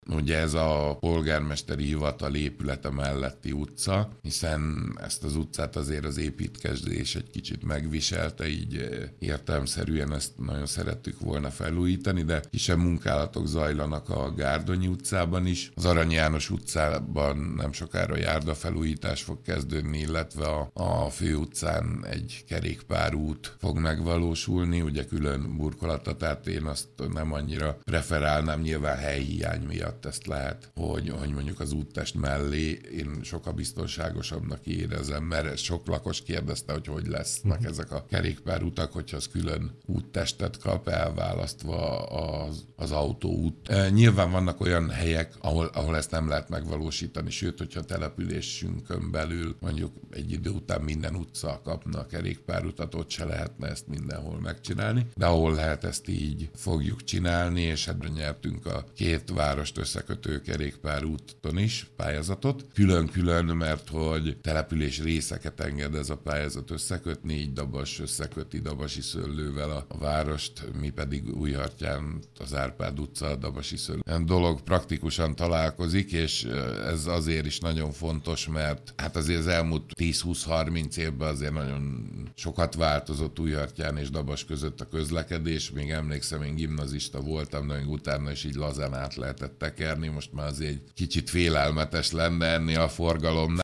A Liget utcában, a Gárdonyi utcában folynak már az útfelújítási munkálatok Újhartyánban, elkezdődik a járdafelújítás az Arany János utcában, a Fő úton pedig kerékpárutat építenek. Utóbbi külön burkolatot kap, tehát az úttest mellé építik a bicikliutat. Schulz József polgármestert hallják.